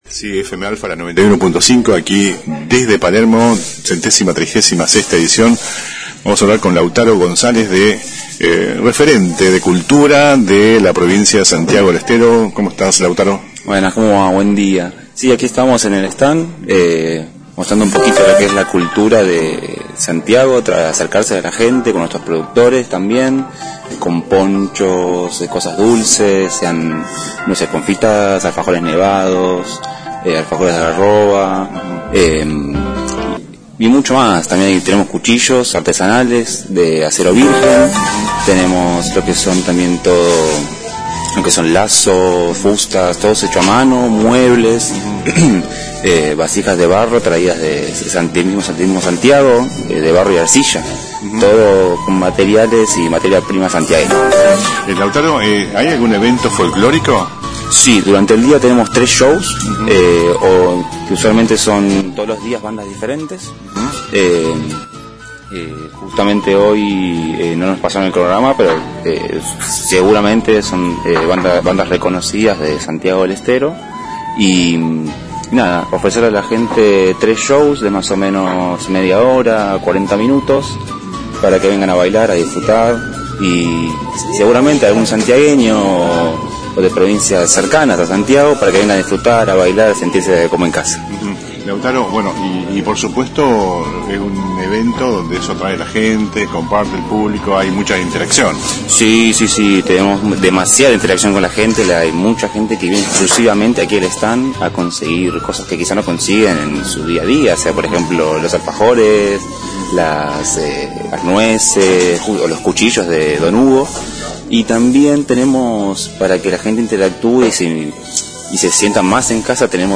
FM Alpha en la Rural de Palermo: hablamos con expositores y visitantes